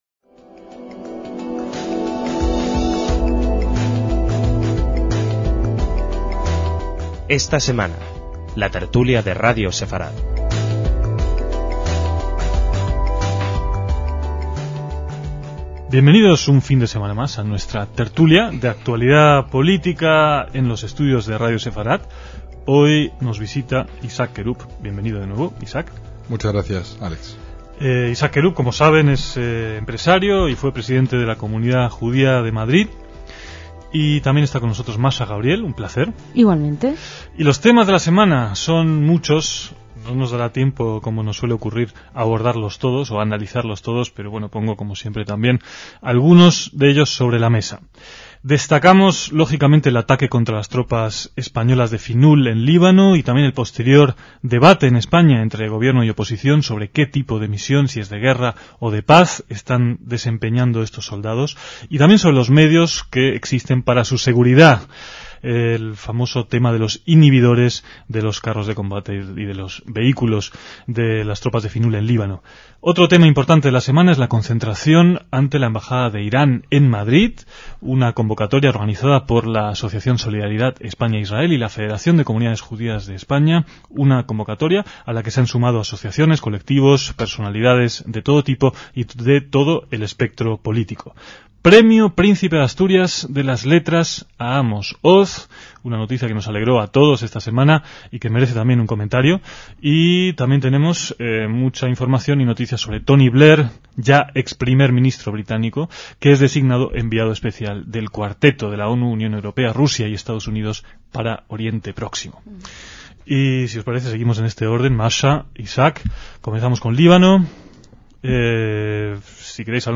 DECÍAMOS AYER (30/6/2007) - En esta "tertulia" participó un único invitado cuyas opiniones sobre diversos temas fueron debatidos con algunos de los miembros de Radio Sefarad.